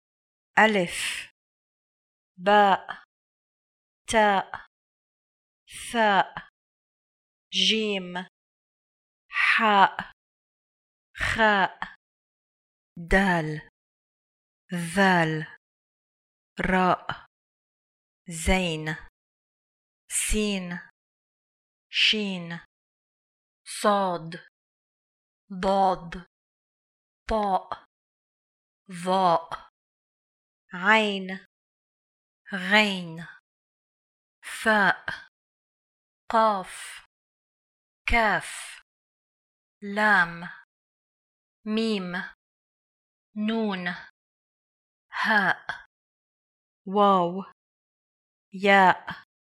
Click below to hear all 28 letters of the Arabic alphabet recited in order:
28-Letters-of-the-Arabic-Alphabet.mp3